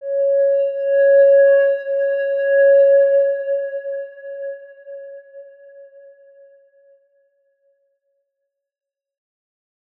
X_Windwistle-C#4-ff.wav